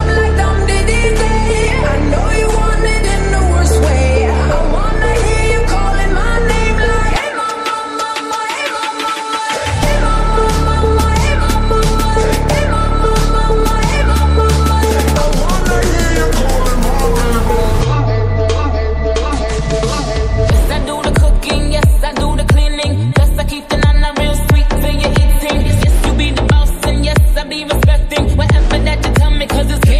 EDM
The song combines electro house and trap.
Ringtone
Electronica